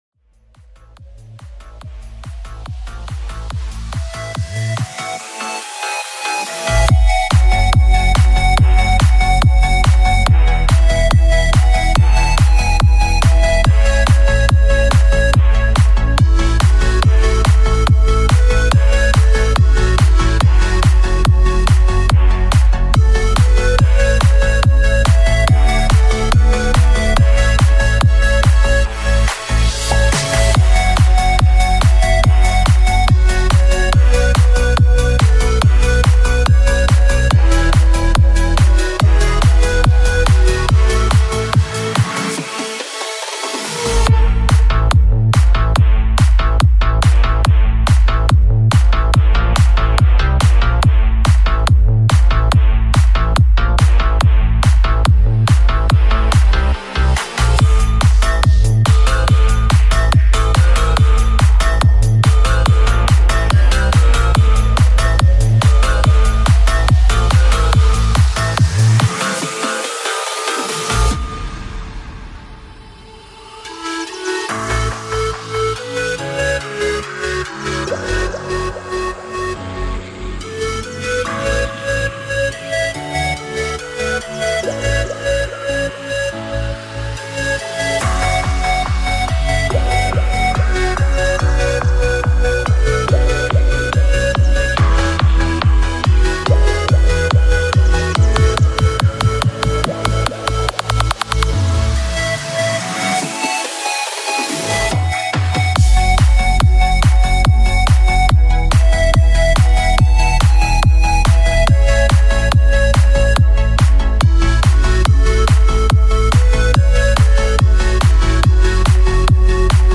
VINAHOUSE MELODY